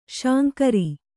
♪ śankari